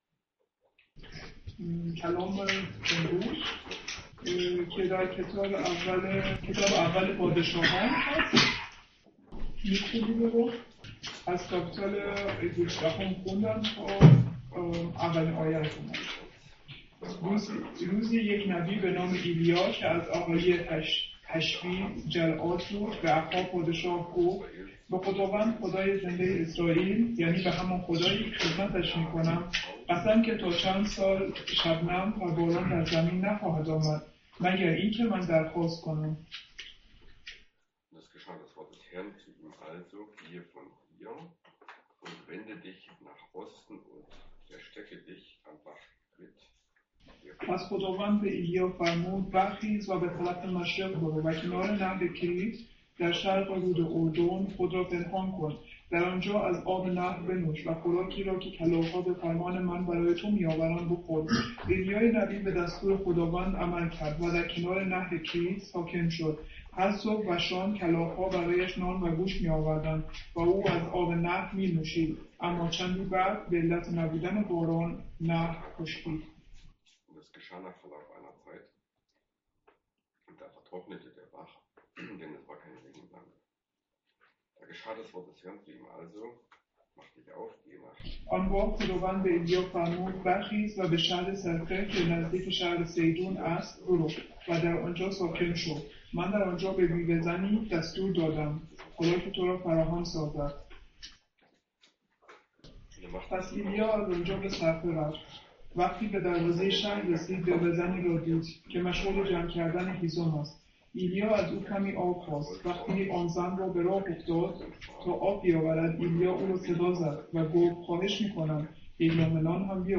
Könige 17,1-16 | Übersetzung in Farsi